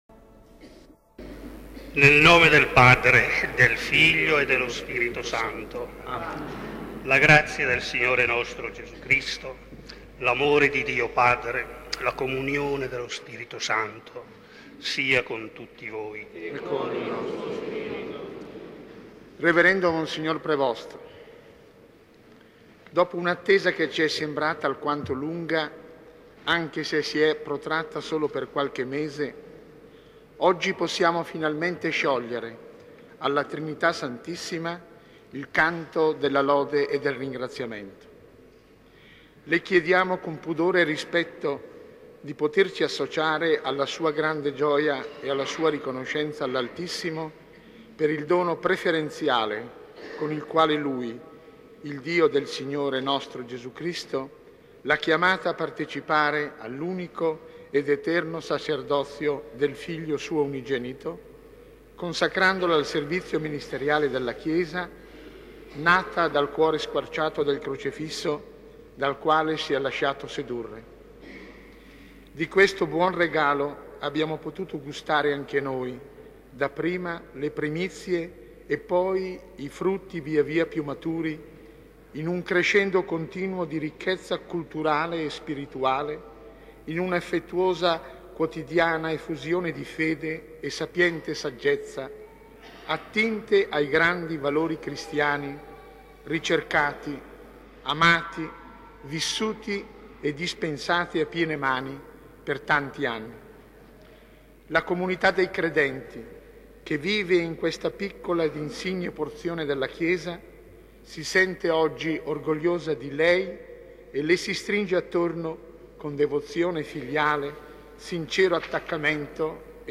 Messa Solenne
S. Alessandro in Colonna